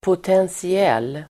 Ladda ner uttalet
potentiell adjektiv, potential Uttal: [potensi'el:] Böjningar: potentiellt, potentiella Synonymer: möjlig Definition: möjlig, slumrande Exempel: ett potentiellt hot mot världsfreden (a potential threat to world peace)